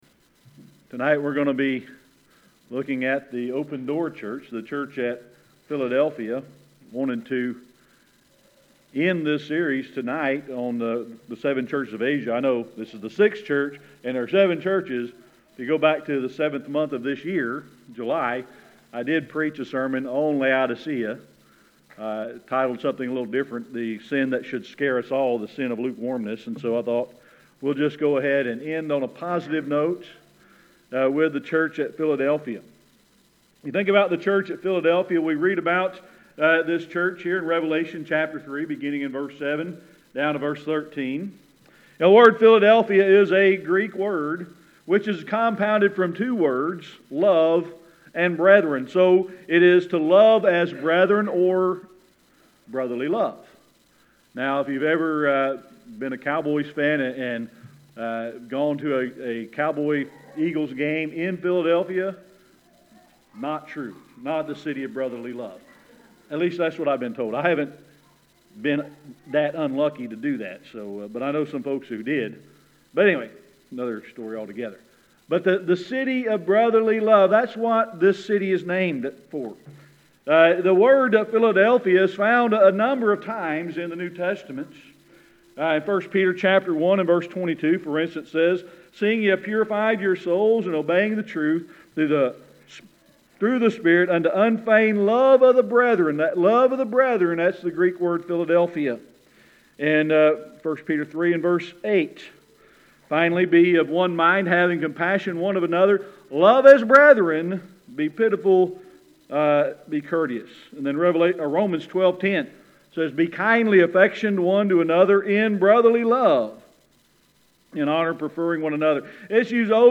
Service Type: Sunday Evening Worship